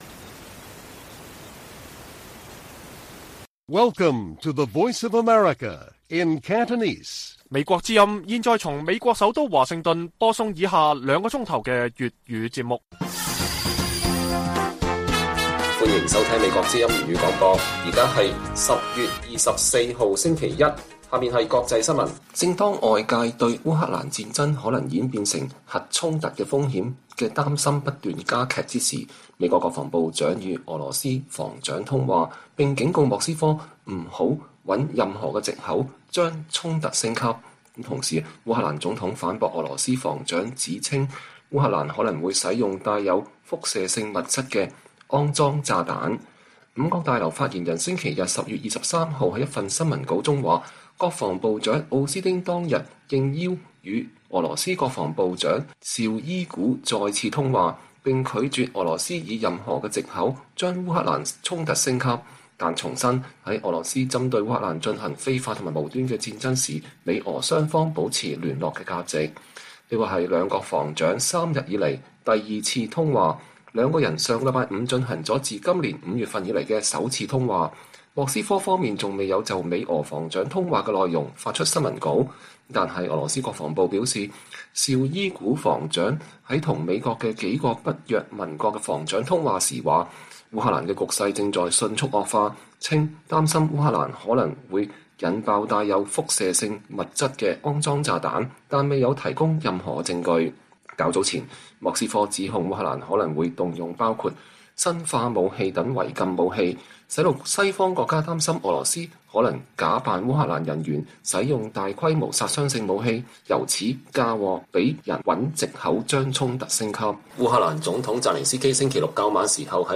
粵語新聞 晚上9-10點: 烏克蘭核衝突風險加劇 美俄防長三天內兩度通話